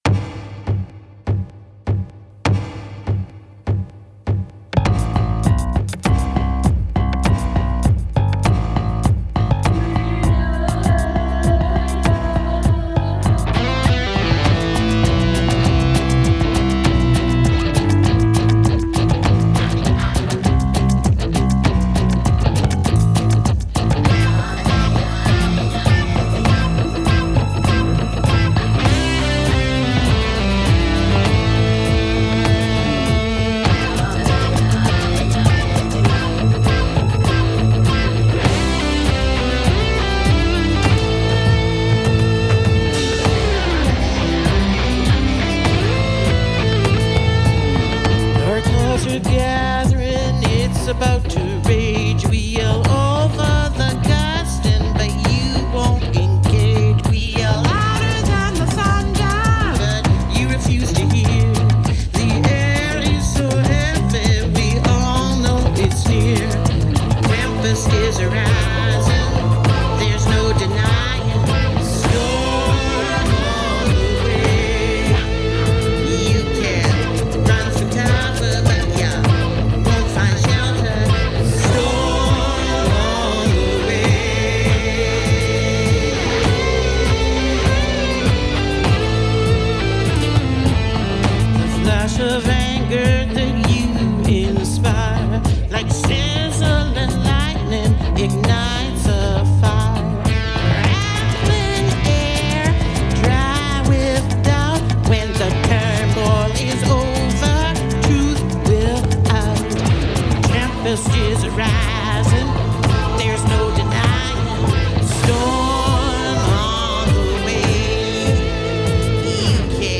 It is the only angry song on my album Pagan Lullabies.